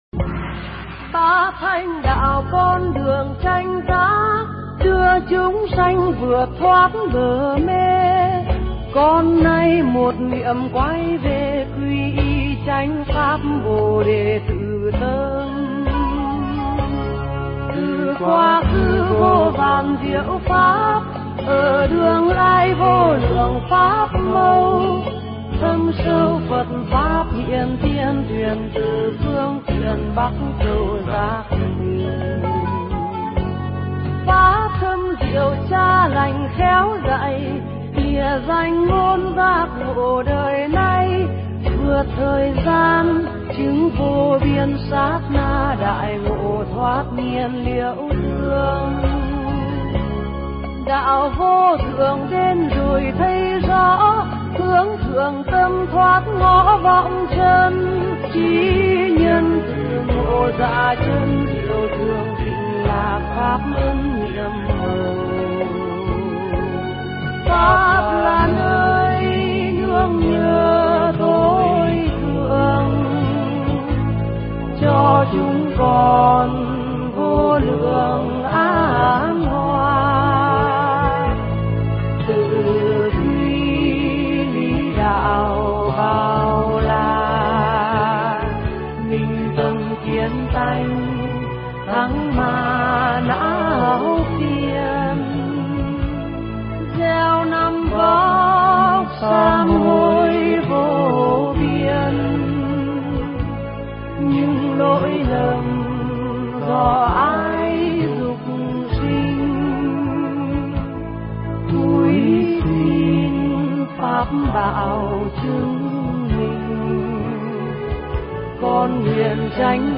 Nghe Mp3 thuyết pháp Hạnh Tu Như Trái Đất